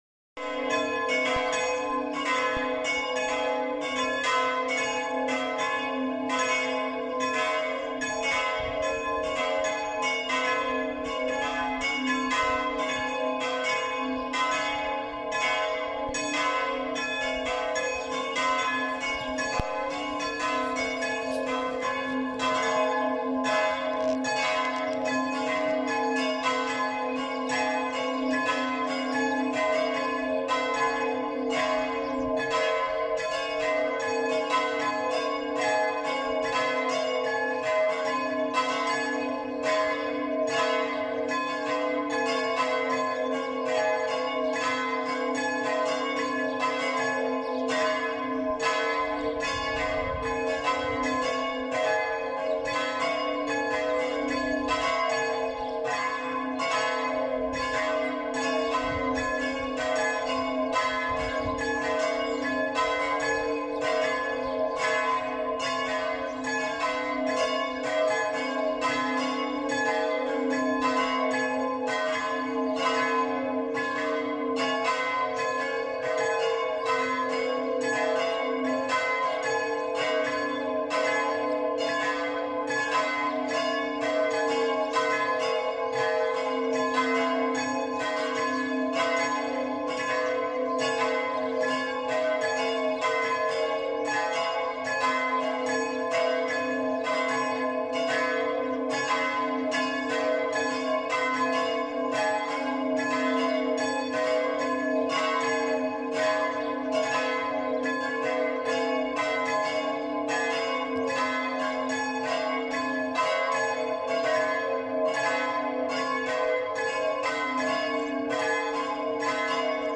Geläut Frankenbach
Gelaeut_Frankenbach.mp3